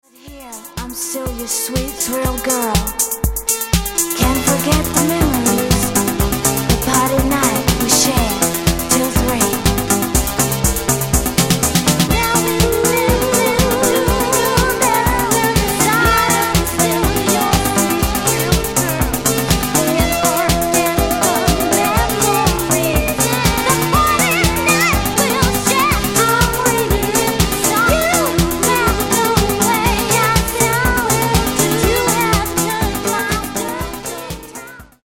Genere:   Disco Elettronica